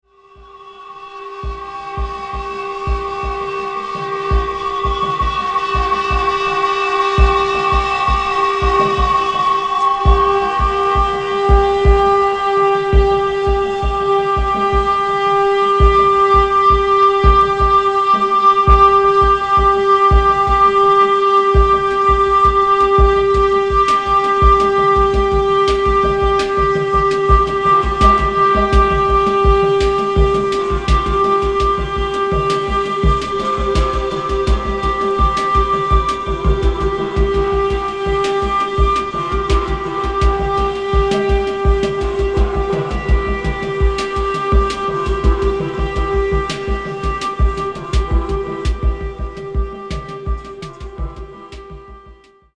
フルート、声、リズムマシーンの他、鳥の鳴き声などの自然音を交えた多重録音による作品。
キーワード：ミニマル　即興　フルート